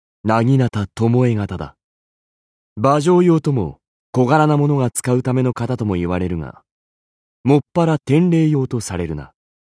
文件 文件历史 文件用途 全域文件用途 巴形图鉴说明.mp3 （MP3音频文件，总共长9.6秒，码率64 kbps，文件大小：75 KB） 巴形图鉴说明语音 文件历史 点击某个日期/时间查看对应时刻的文件。